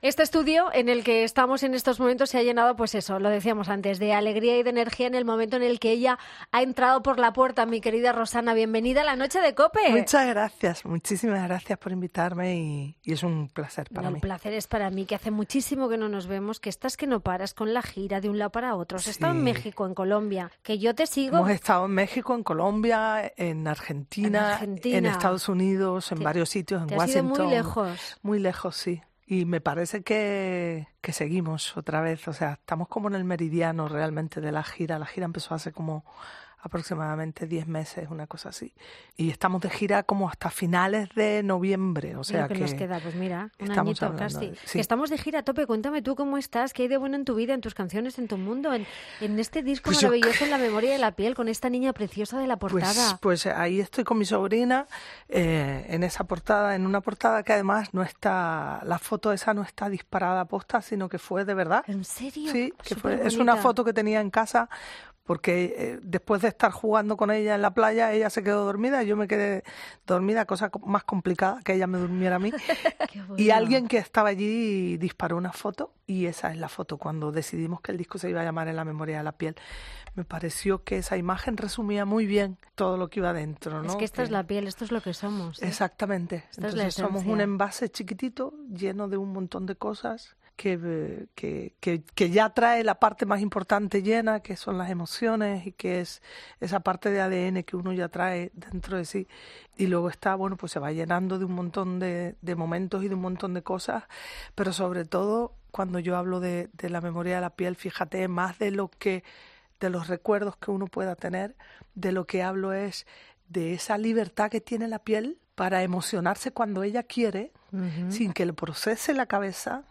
AUDIO: Presentándonos en el estudio, aterriza Rosana Arbelo para compartir su disco La Memoria de la Piel, a tan solo 1 semana de su concierto en el...